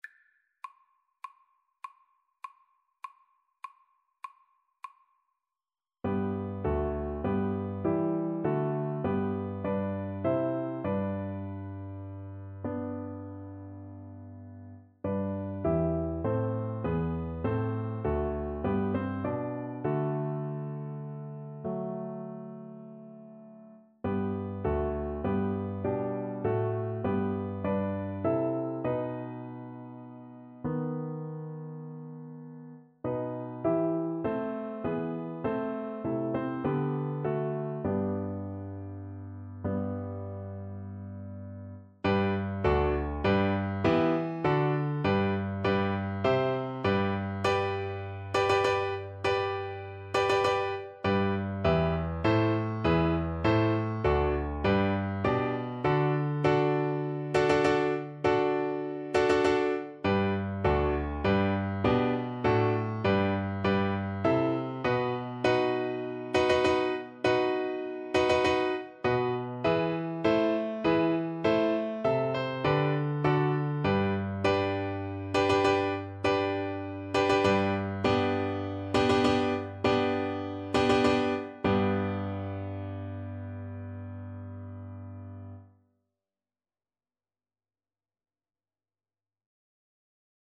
Play (or use space bar on your keyboard) Pause Music Playalong - Piano Accompaniment Playalong Band Accompaniment not yet available transpose reset tempo print settings full screen
Maestoso
G major (Sounding Pitch) (View more G major Music for Cello )
Classical (View more Classical Cello Music)